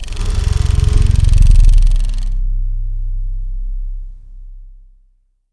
rodextend.ogg